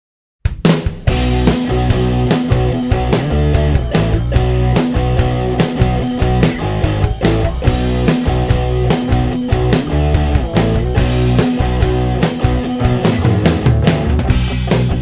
Sound-Alikes